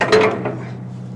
Metal Hook On Boat